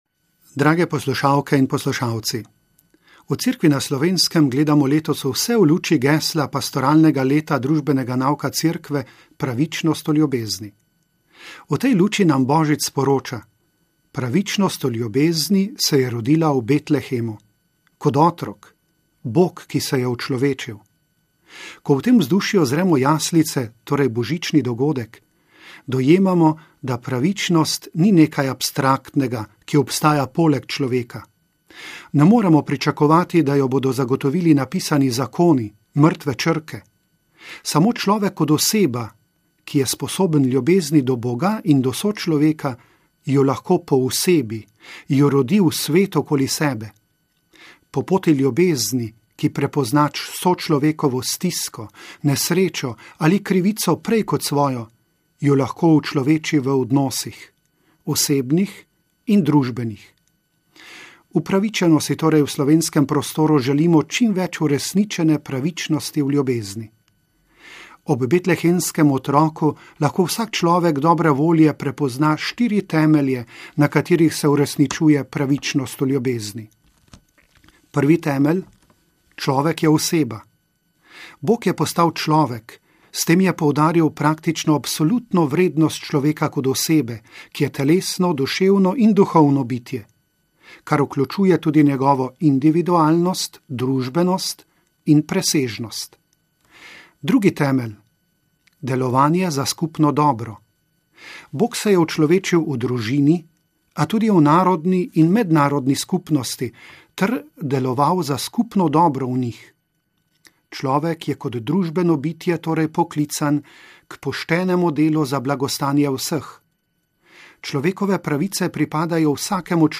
BOŽIČNO VOŠČILO nadškofa Marjana Turnška